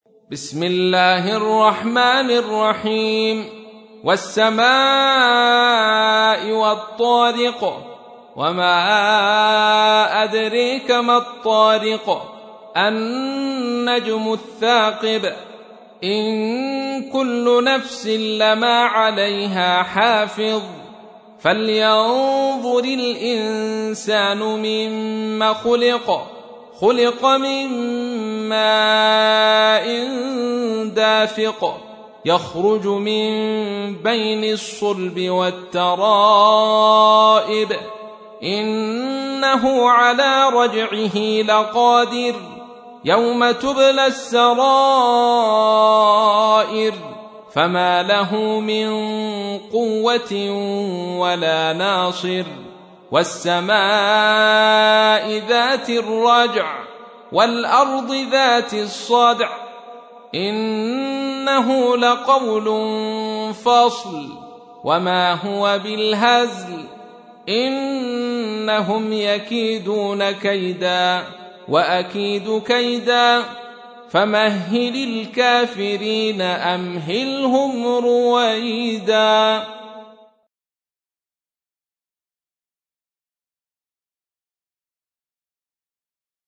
تحميل : 86. سورة الطارق / القارئ عبد الرشيد صوفي / القرآن الكريم / موقع يا حسين